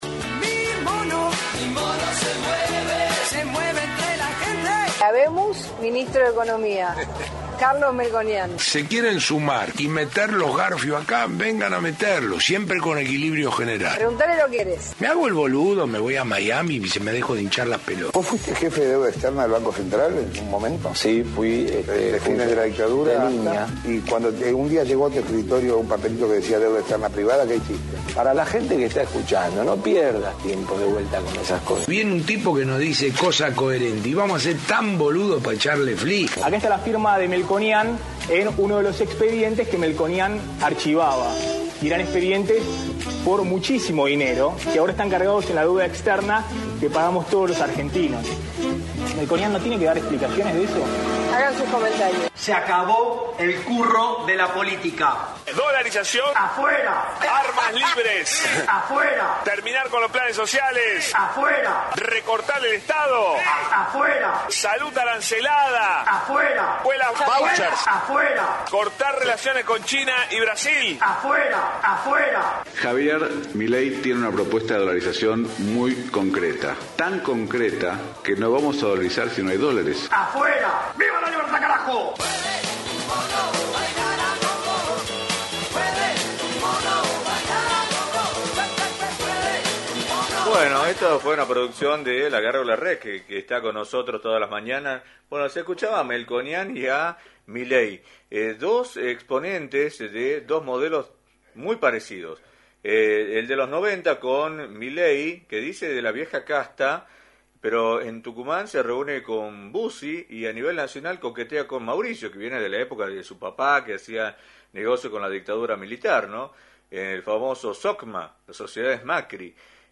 diálogo exclusivo